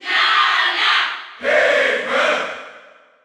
File:Ice Climbers Cheer German SSBU.ogg
Ice_Climbers_Cheer_German_SSBU.ogg